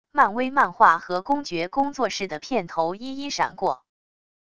漫威漫画和公爵工作室的片头一一闪过wav音频